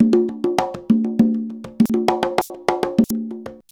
133CONGA05-R.wav